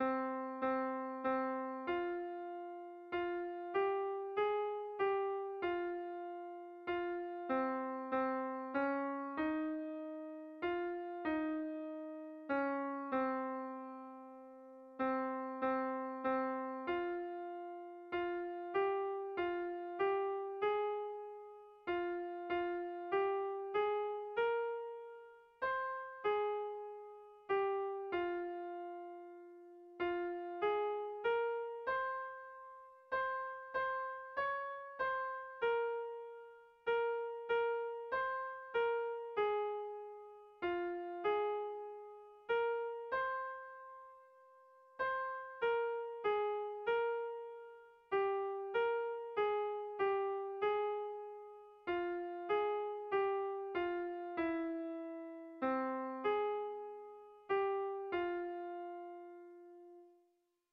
Erlijiozkoa
Zortziko handia (hg) / Lau puntuko handia (ip)
ABDE